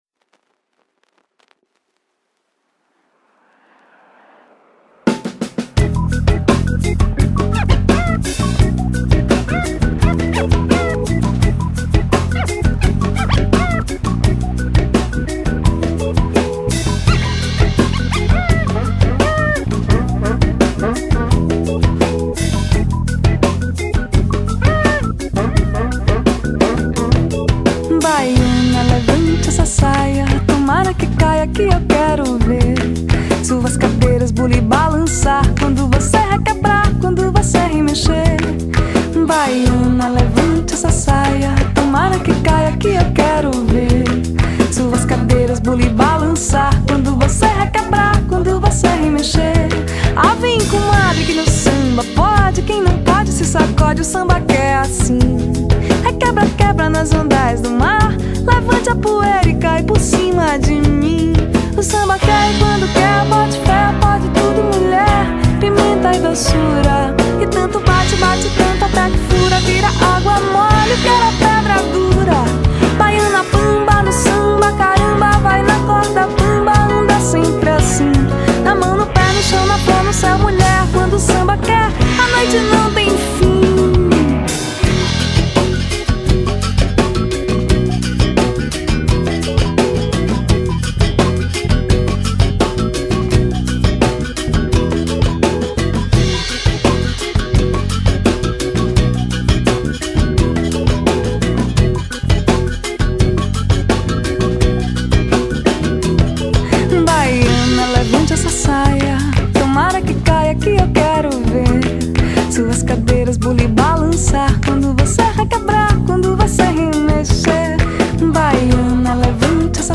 Se você fizesse isso e depois esse material sofresse algum tipo de manipulação, o som poderia ficar dessa forma E quem sabe você possa usar esse som no seu patch escrito em Pure Data.